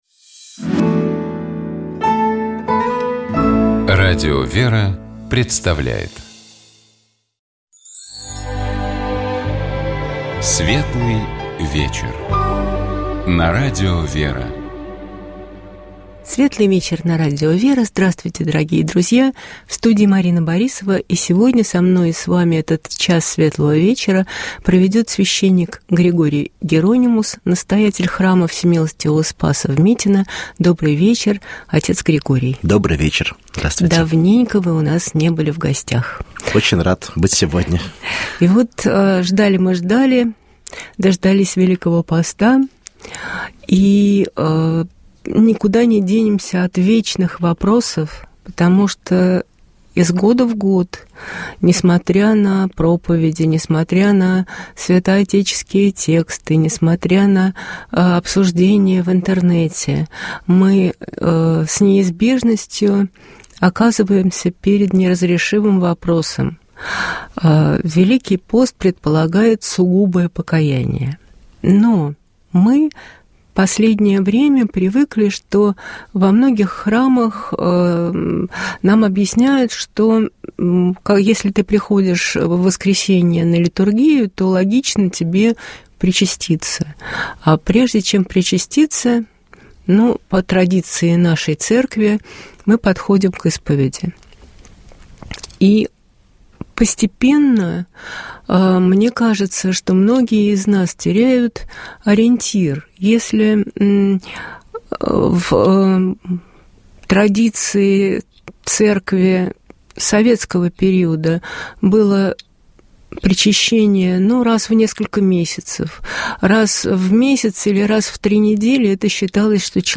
Программа «Светлый вечер» — это душевная беседа ведущих и гостей в студии Радио ВЕРА. Разговор идет не о событиях, а о людях и смыслах.